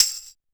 Shaken Tamb 02.wav